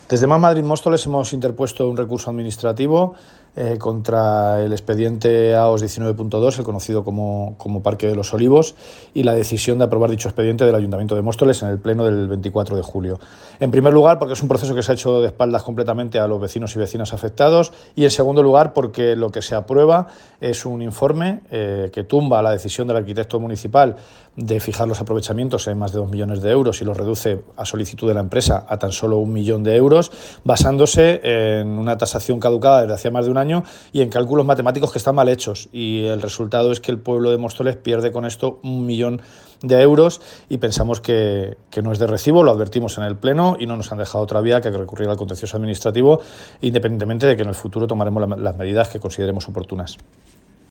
Emilio Delgado. Declaraciones Parque de los Olivos
declaraciones-emilio-delgado-parque-olivos.mp3